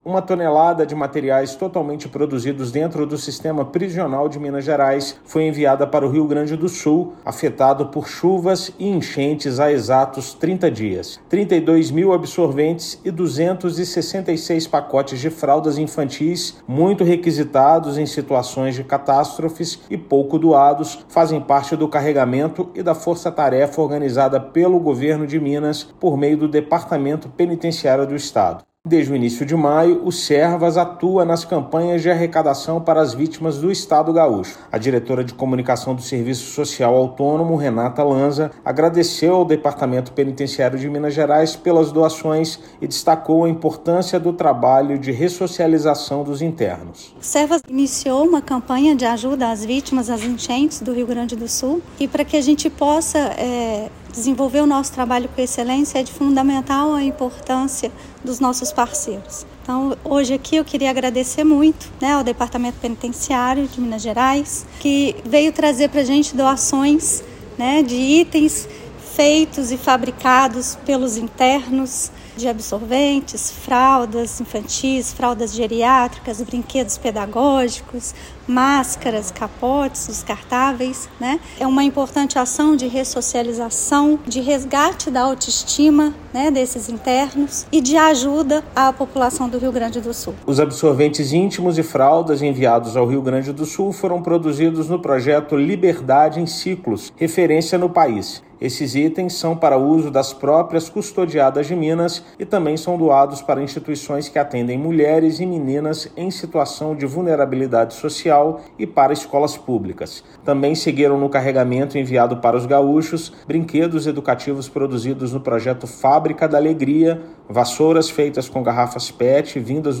Entre os itens estão absorventes, fraldas, brinquedos, vassouras e produtos hospitalares; policiais penais já reforçam quadro das unidades prisionais locais há 20 dias. Ouça matéria de rádio.